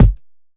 DillaSnapKick.wav